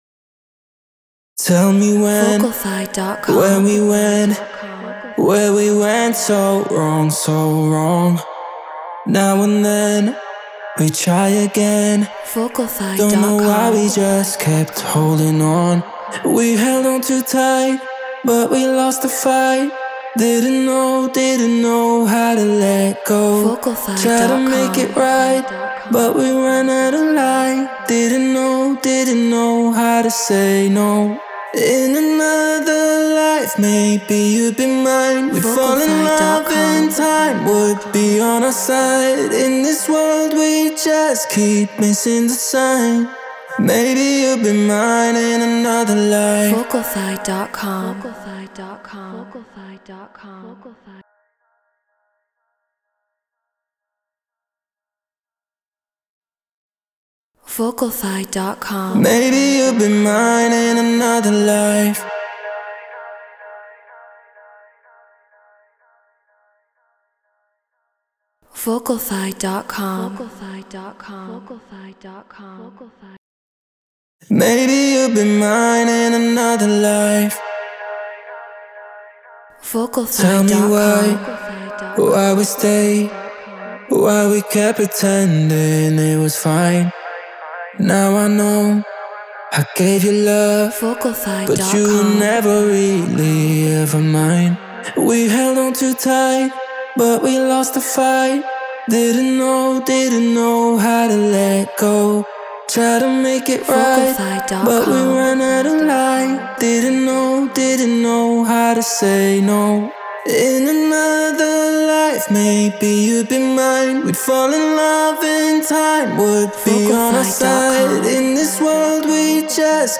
Progressive House 125 BPM G#maj
Neumann TLM 103 Focusrite Scarlett Pro Tools Treated Room